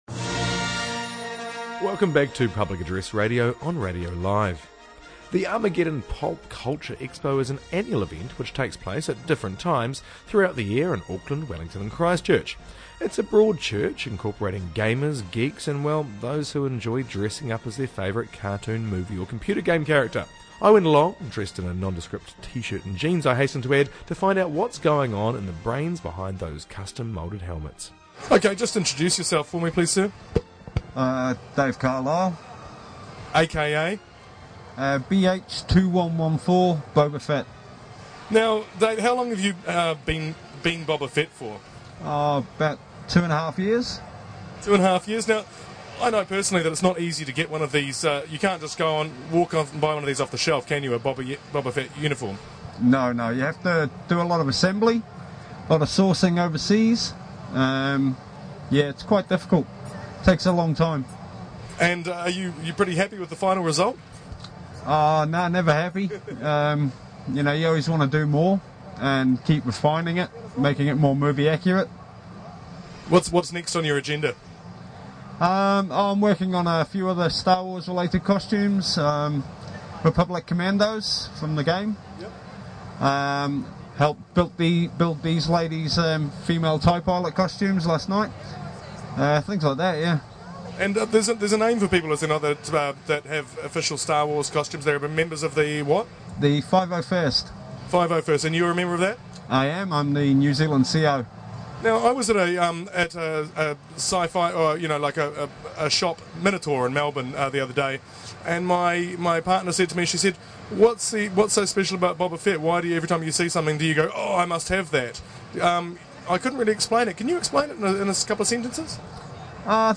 Armageddon Pulp Culture Expo